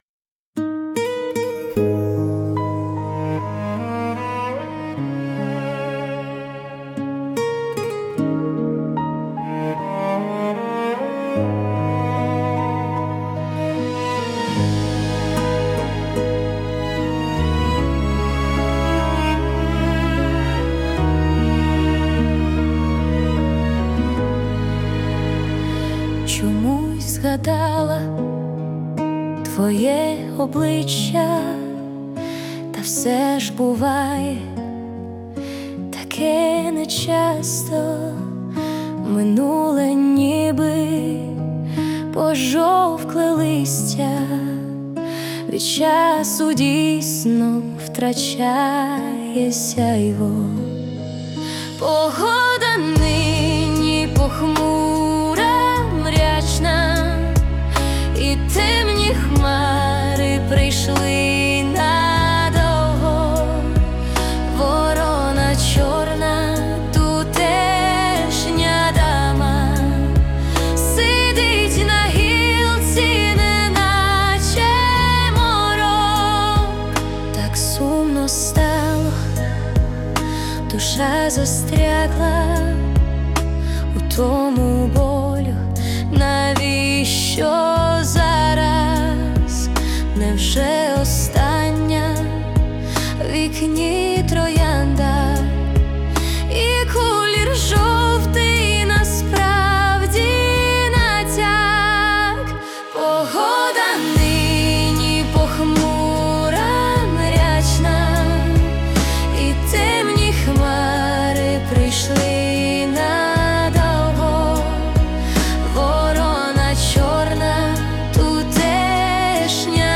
Музична композиція створена за допомогою SUNO AI
СТИЛЬОВІ ЖАНРИ: Ліричний
Чудова пісня! 16